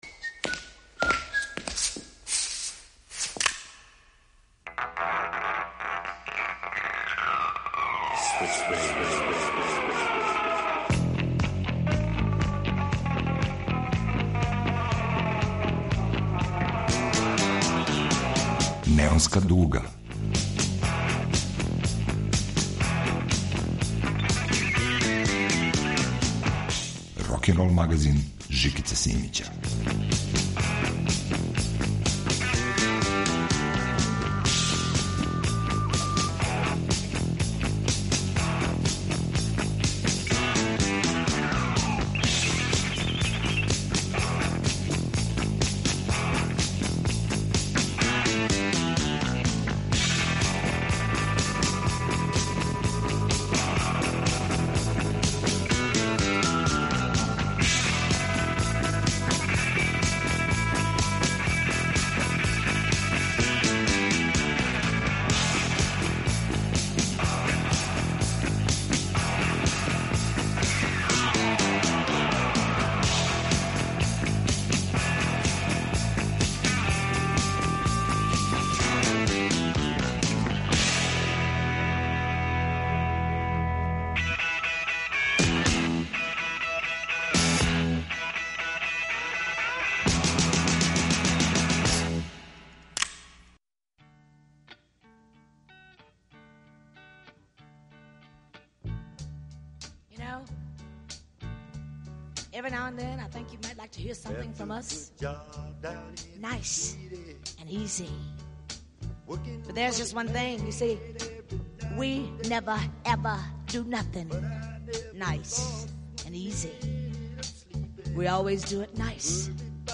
рокенрол магазин